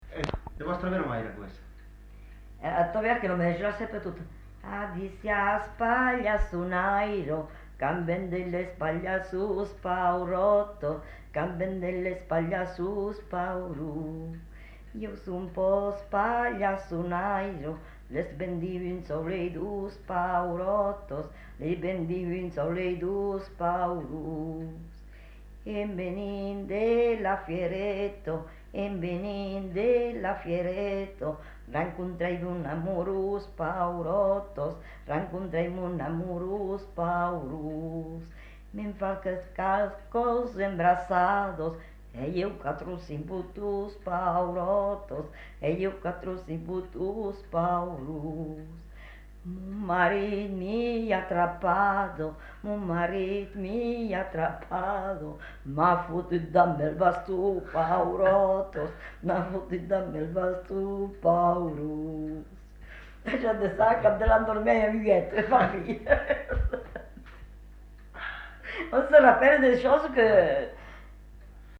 Aire culturelle : Lauragais
Lieu : Lanta
Genre : chant
Effectif : 1
Type de voix : voix de femme
Production du son : chanté